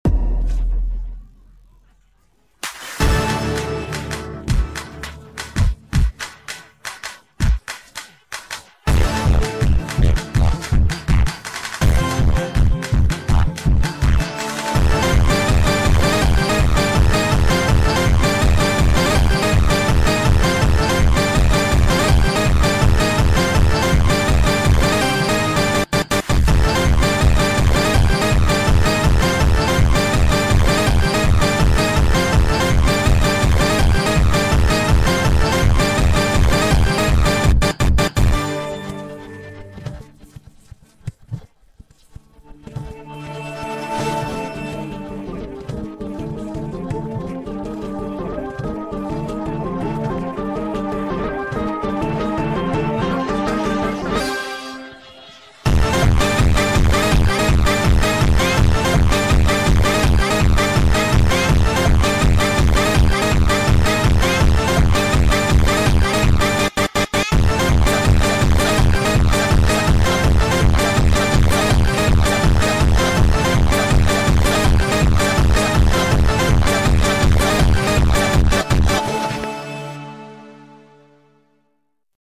فانکی خفن با ریتمی تند در ورژن Sped Up
فانک